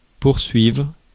Ääntäminen
Tuntematon aksentti: IPA: /puʁ.sɥivʁ/